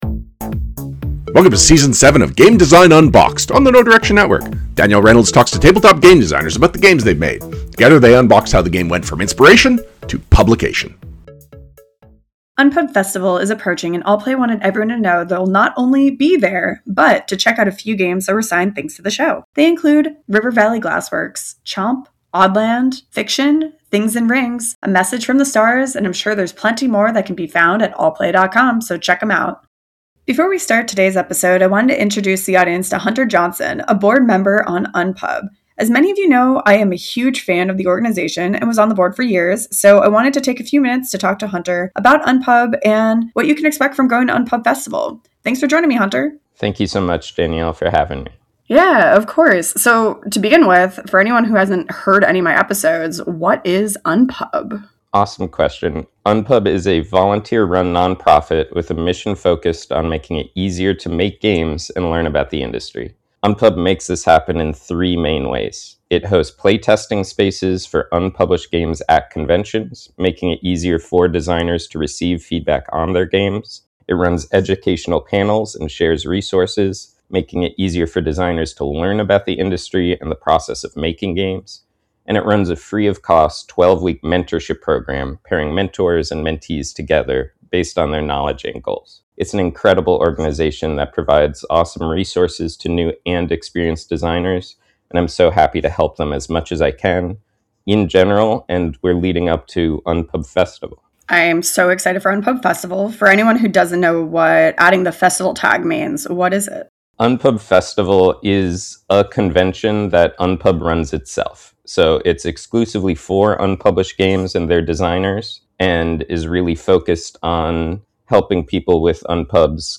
This episode also opens with a brief bonus interview previewing March’s upcoming UNPUB Festival, an annual event hosted by the volunteer organization UNPUB focused on unpublished games and designers. The intro-chat highlights the event’s offerings, from industry-led panels and workshops to large-scale playtesting opportunities.